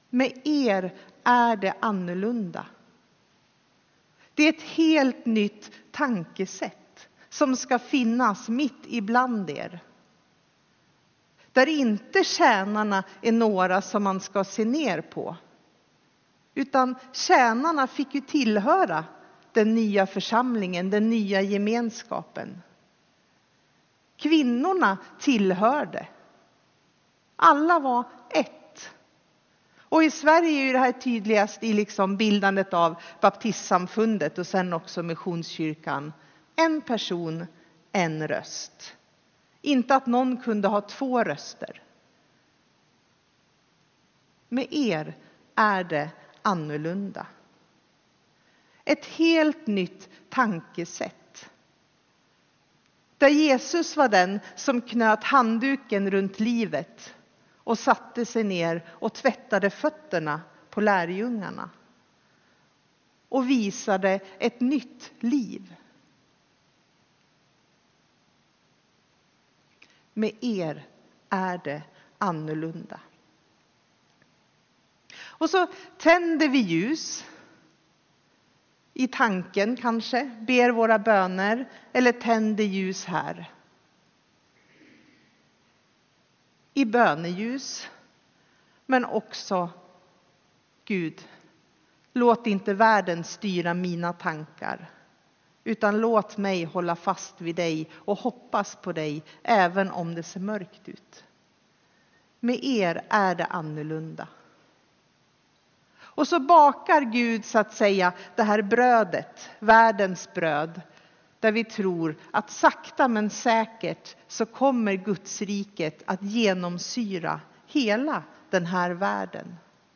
Tema för gudstjänsten är ”Enheten i Kristus”. Texten är hämtad från Lukas evangelium 22:24-27.